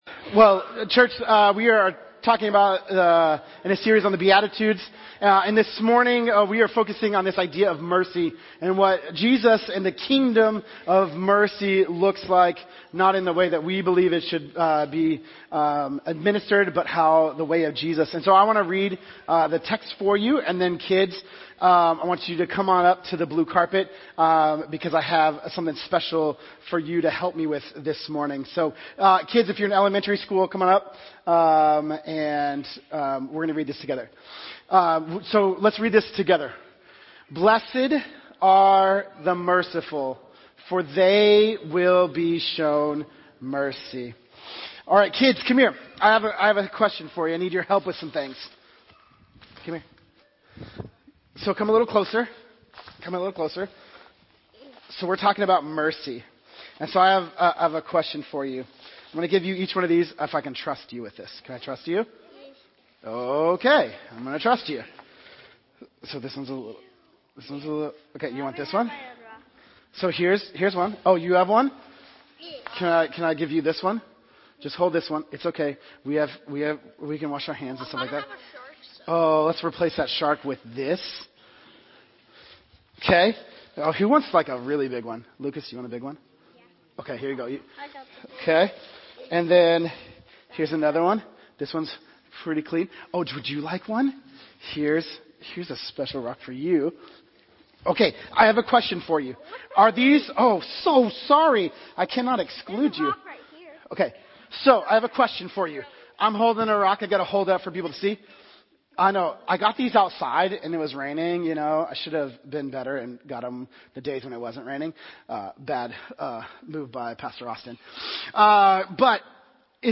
Discipleship Sunday is designed to be hands-on and multigenerational—full of participation, questions, curiosity, and conversation. As we continue our series, “The Beatitudes” (Matthew 5:2–10), we’ll focus on Matthew 5:7 and the gift (and challenge) of mercy. We’ll name the weight we carry when we don’t offer mercy to others, and when we’re slow to accept the mercy God offers us for our sin.